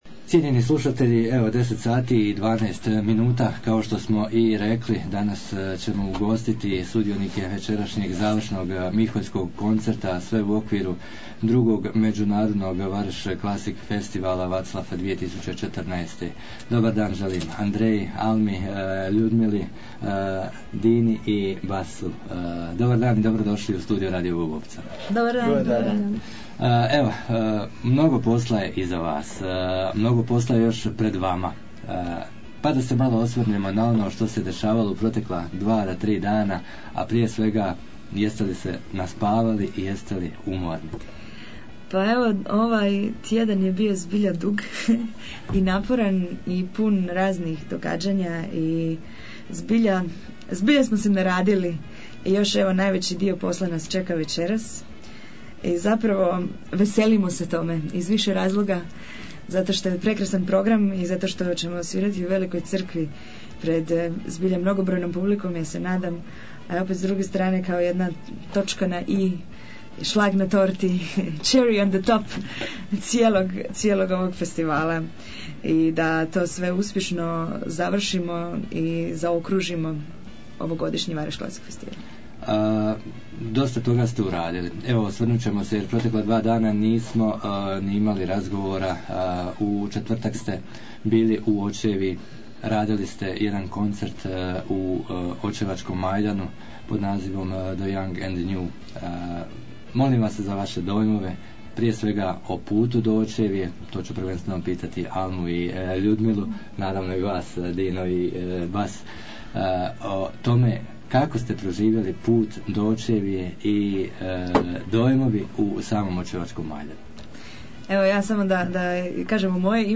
Na sam dan završnog koncerta 27.09.2014. godine ugostili smo sudionike i razgovarali o protekla dva dana te o večerašnjem nastupu